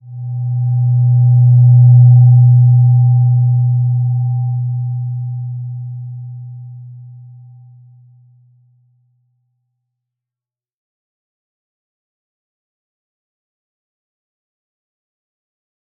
Slow-Distant-Chime-B2-mf.wav